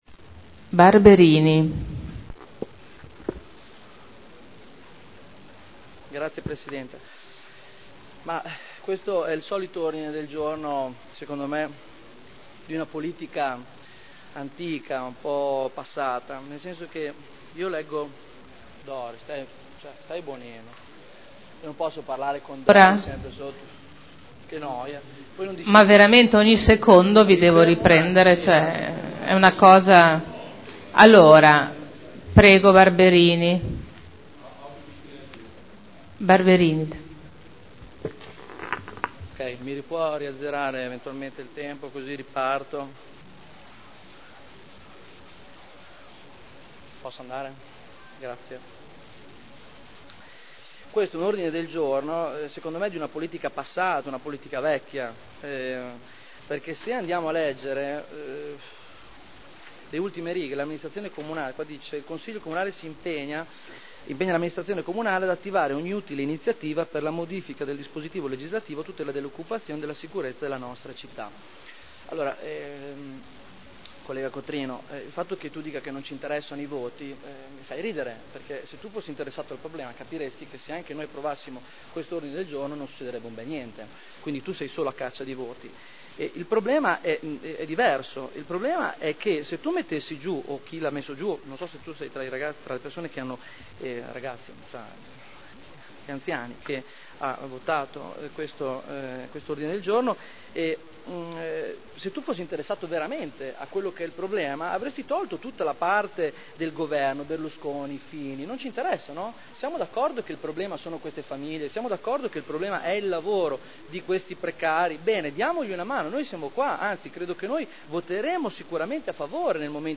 Stefano Barberini — Sito Audio Consiglio Comunale
Seduta del 15/11/2010. Dibattito su Ordine del Giorno presentato dai consiglieri Dori, Trande, Andreana, Rocco, Pini, Garagnani, Urbelli, Prampolini, Cornia, Goldoni, Glorioso, Cotrino, Sala, Gorrieri, Codeluppi, Guerzoni, Rimini, Artioli (P.D.) e Ricci (Sinistra per Modena) avente per oggetto: “Riduzione del personale presso il Ministero dell’Interno – uffici per l’immigrazione”